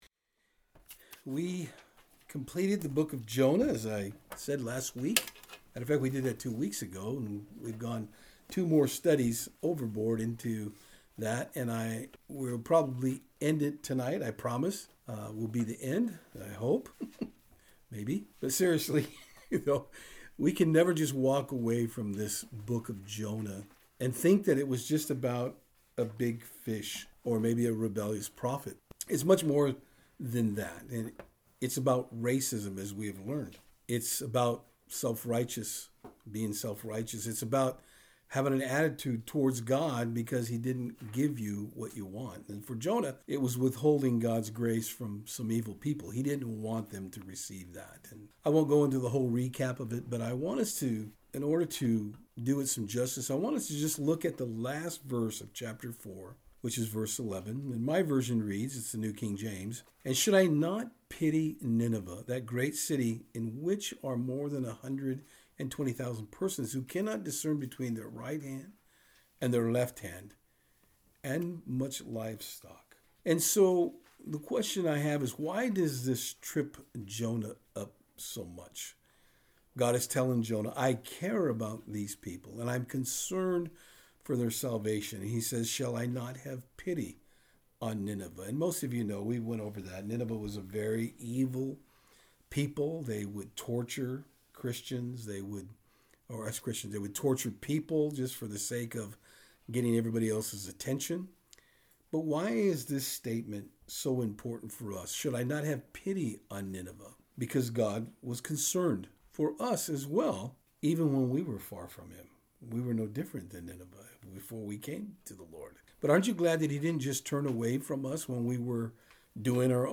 Various O.T Scriptures Service Type: Saturdays on Fort Hill In our final addition of the Book of Jonah we are going to look at where our Nineveh’s are in our lives.